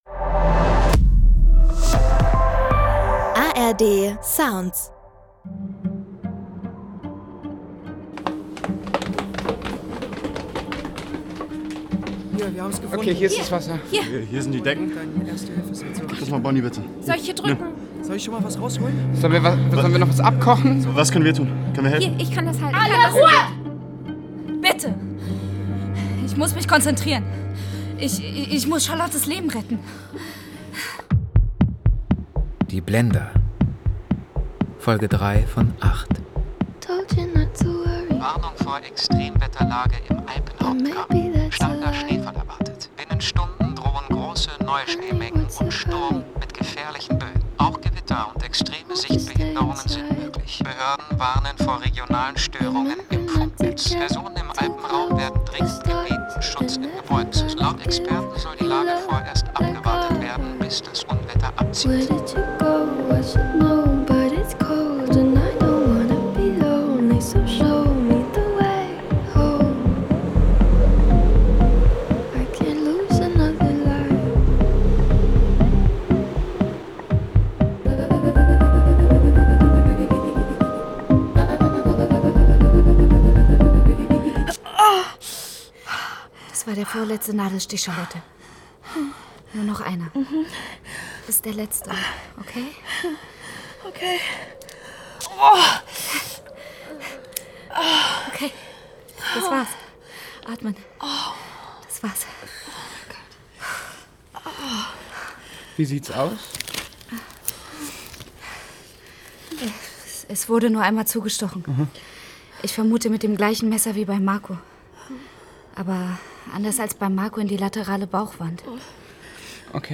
Die Blender: Charlotte (3/8) – Niemand kann entkommen ~ Die Blender – Crime-Hörspiel-Serie Podcast